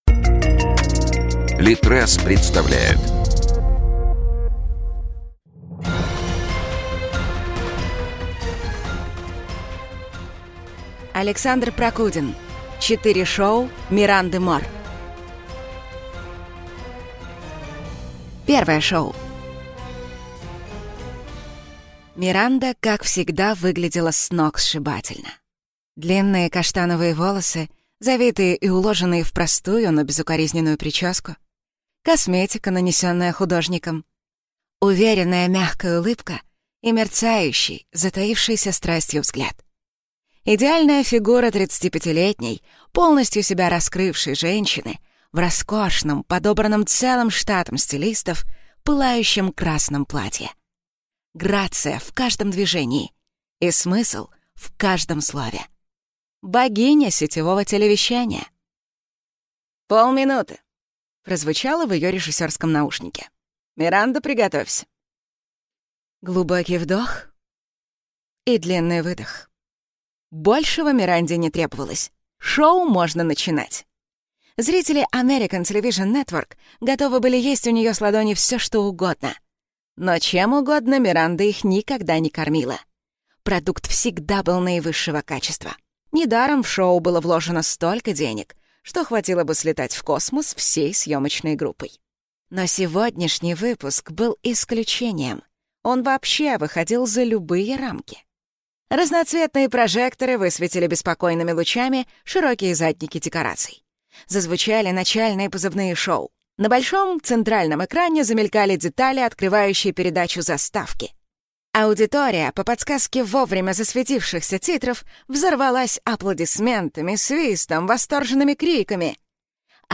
Аудиокнига Четыре шоу Миранды Мор | Библиотека аудиокниг